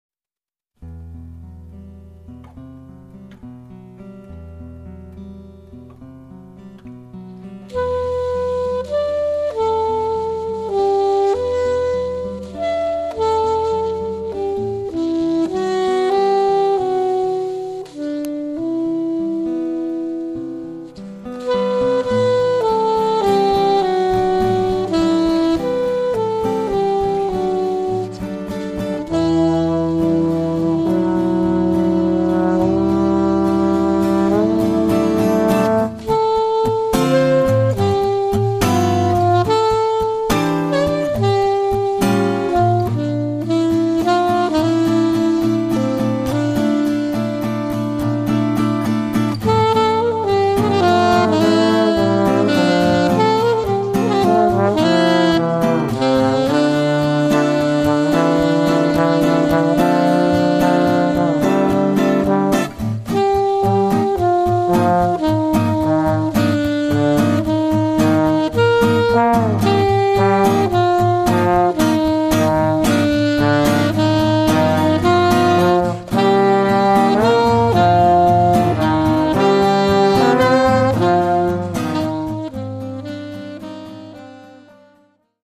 chitarre
trombone
sax e clarinetto